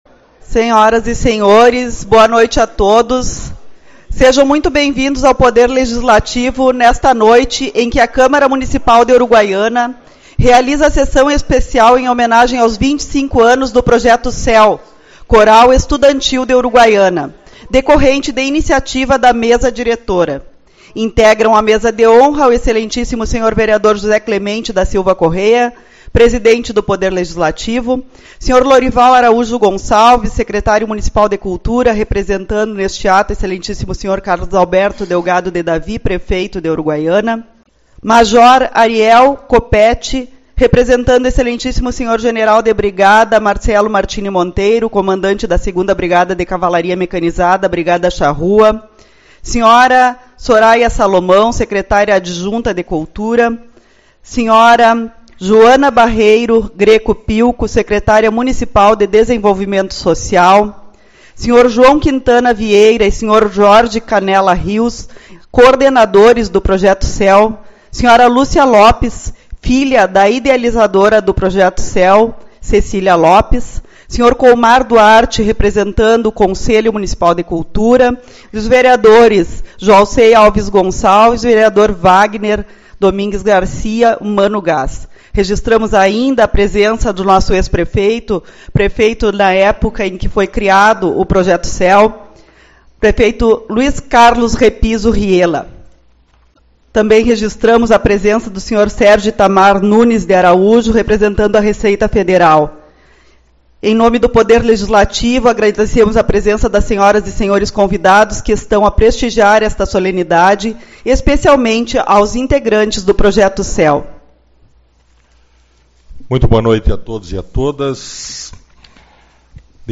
25/03 - Sessão Especial-25 Anos do Projeto CEU